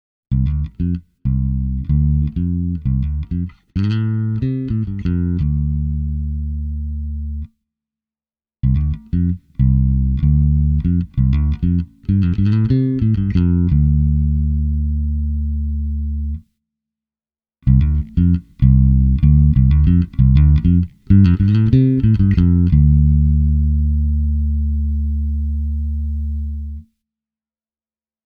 Minikytkimen High-asento on tehdasasenteinen ja se tarjoaa todella pyöreän kompressiosoundin isolla (6 dB) vahvistuksella.
Billy Sheehan -nimikkopedaalin kompressori toimii hyvin musikaalisella tavalla, ja se tarjoaa rutkasti gainea.
Tässä on puhtaalla soundilla äänitetty näyte EBS-efektin kolmesta kompressoriasetuksista:
clean-compression.mp3